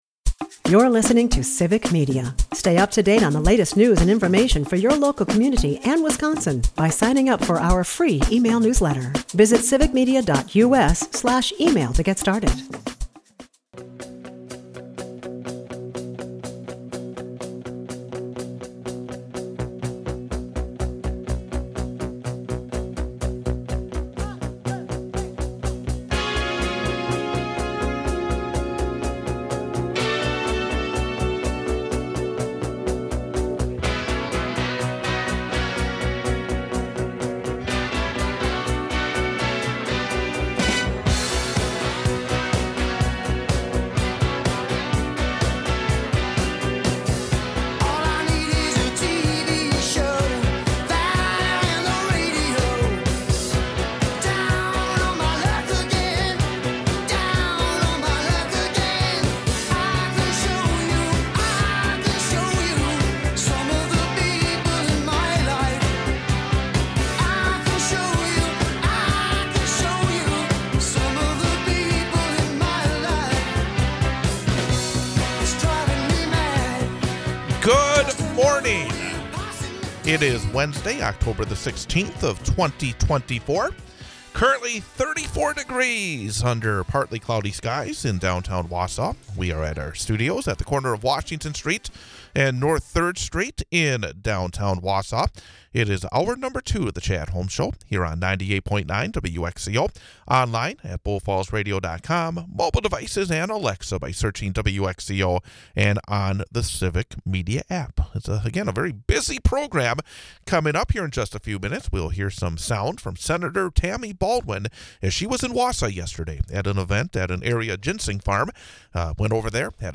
We hear comments from Senator Tammy Baldwin at a visit Tuesday to a Wausau ginseng farm as the Senator continued her Wisconsin Farm Tour.